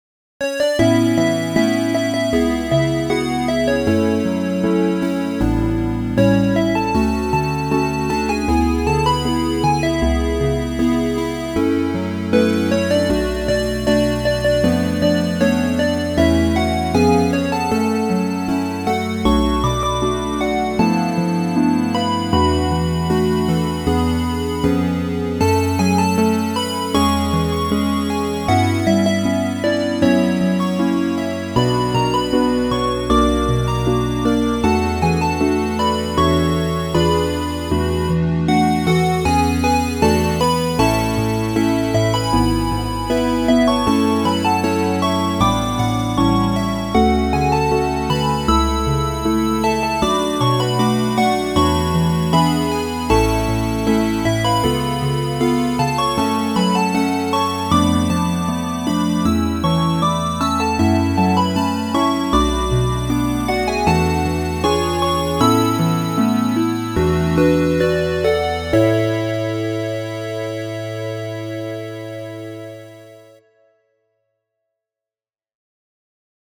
メロディ音源
「このまちと ともに～丹波市の歌～」屋外防災行政無線試験メロディ（昼） (音声ファイル: 1.5MB)